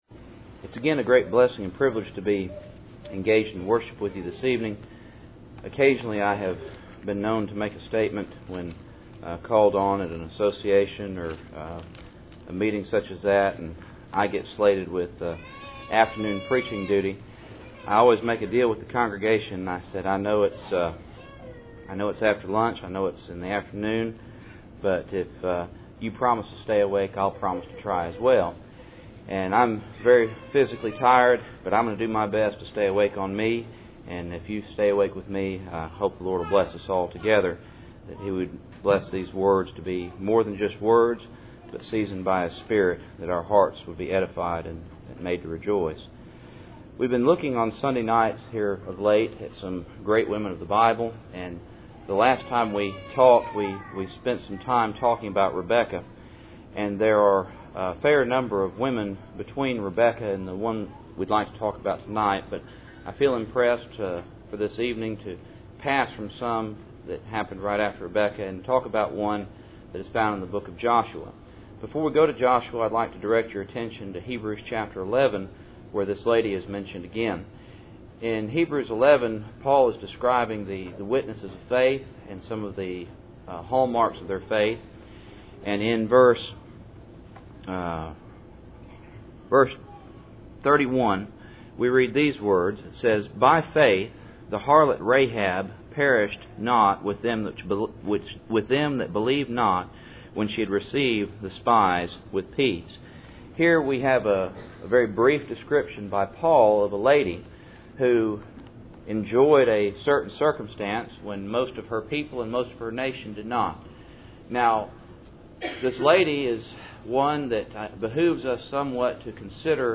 Passage: Joshua 2:9-20 Service Type: Cool Springs PBC Sunday Evening